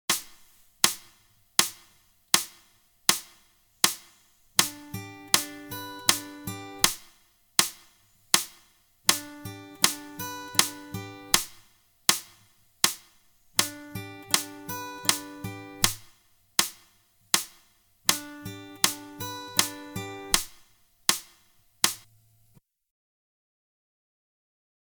Half Speed mp3
Repeats 4X